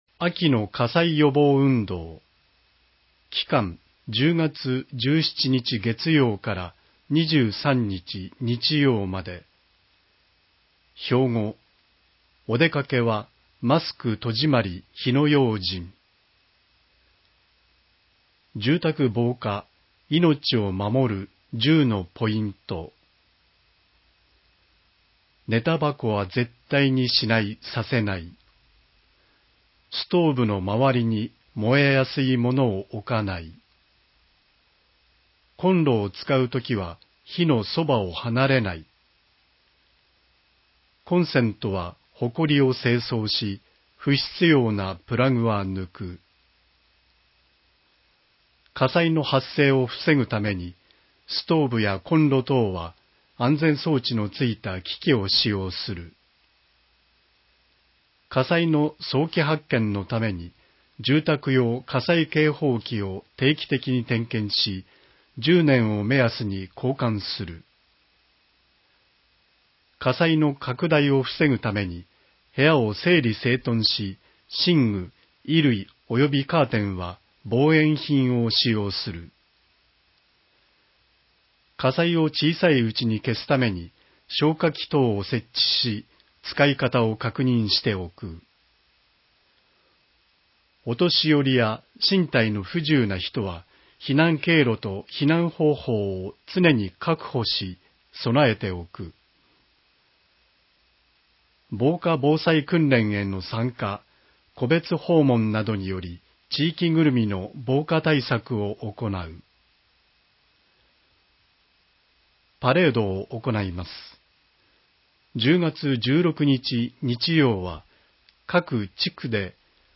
音声は、ボランティアグループ「やまびこの会」が朗読録音したものです。